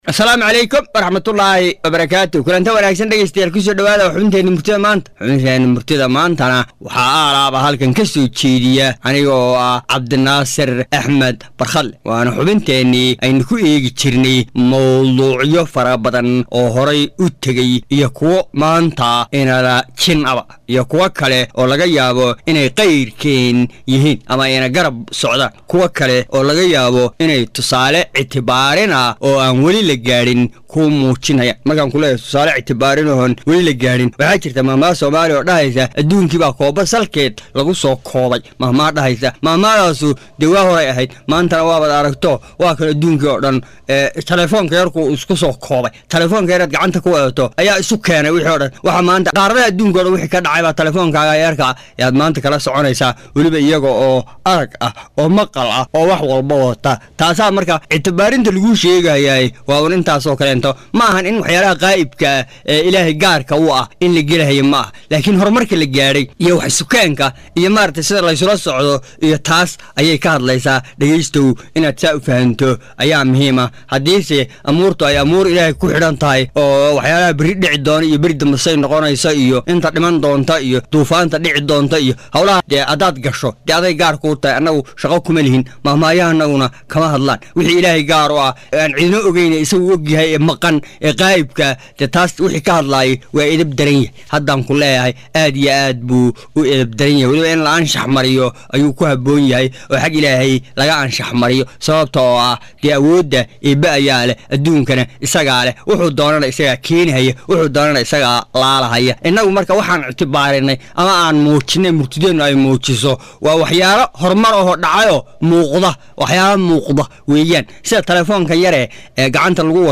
Soo jeedinta abwaan